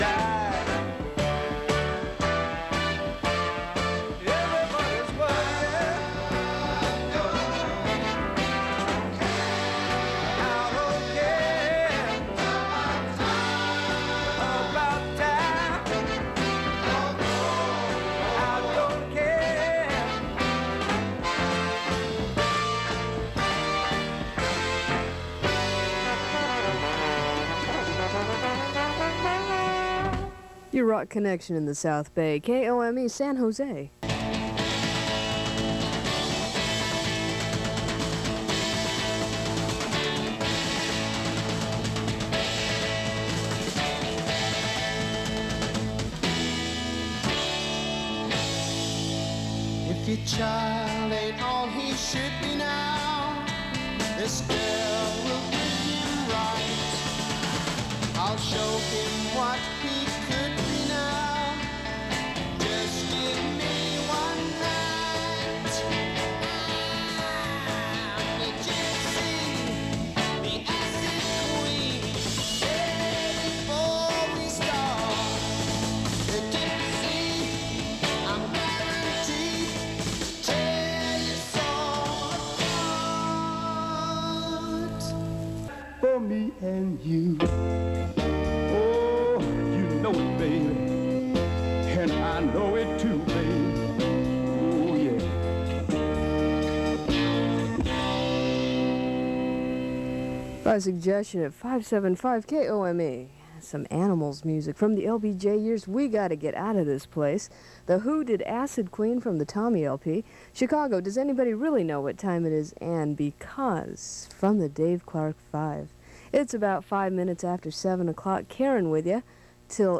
Fiddling around with my AM stereo Transmitter and recorded a partially scoped air check from Kome FM, San Jose ( 1979) have not uploaded this particular segment to the site. I took the feed directly from the reel to reel player ( recorded at 3.75 IPS).
I think I have reduced the hum that I got on previous tests but there is still some ( what can you do it's AM) and of course tape hiss. KOME FM AM Stereo
KOME-FM-via-AM-Stereo.mp3